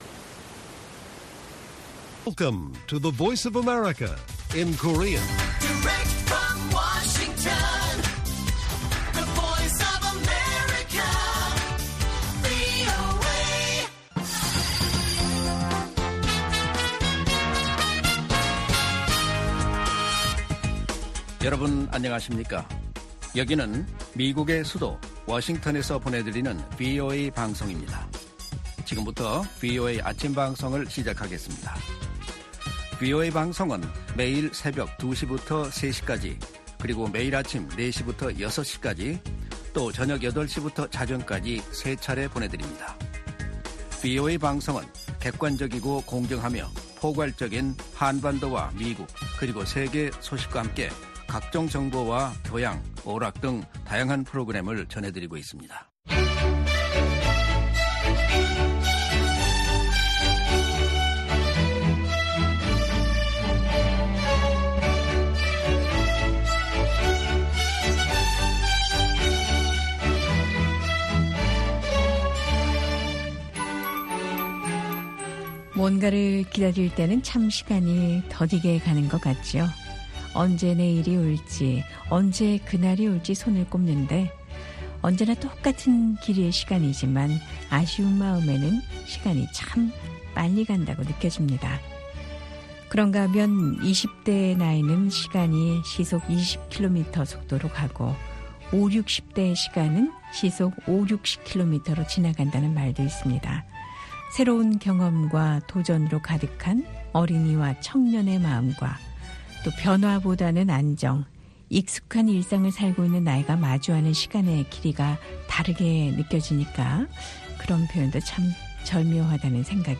VOA 한국어 방송의 일요일 오전 프로그램 1부입니다. 한반도 시간 오전 4:00 부터 5:00 까지 방송됩니다.